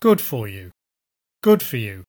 So we get GOOD for you. The word for may or may not have a weakened vowel, but the intonation accent is on good:
good_for_you_beneficial.mp3